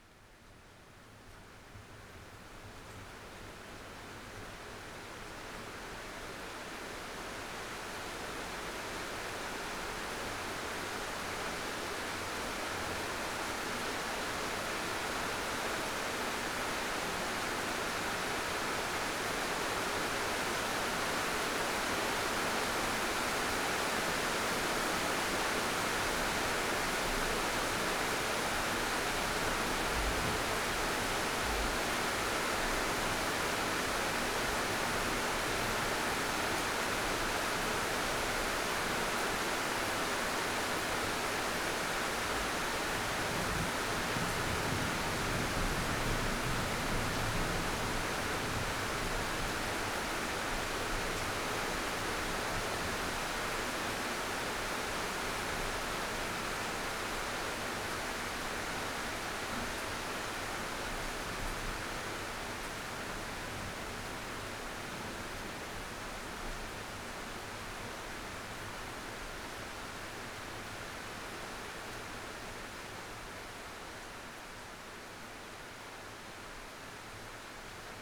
Index of /audio/samples/SFX/IRL Recorded/Rain - Thunder/
Rain 6.wav